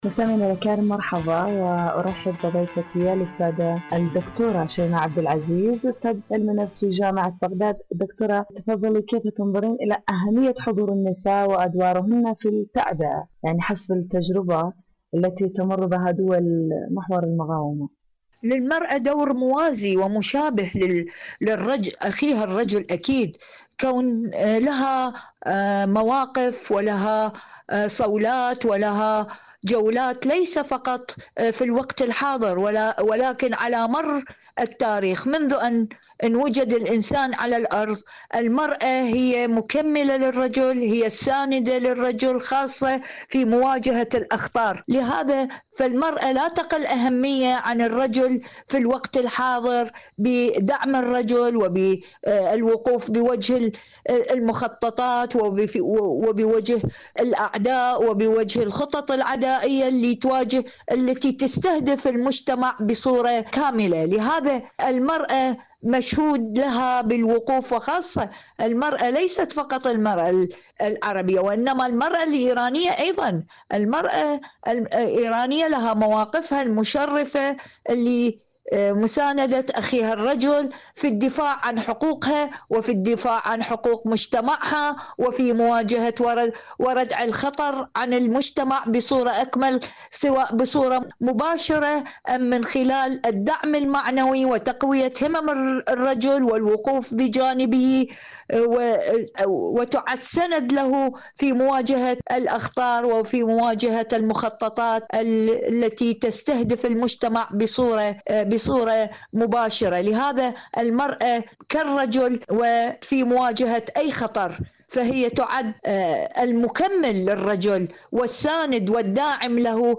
المرأة التعبوية، مواصفات وأدوار.. مقابلة